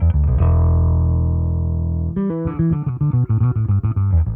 Index of /musicradar/dusty-funk-samples/Bass/110bpm
DF_PegBass_110-E.wav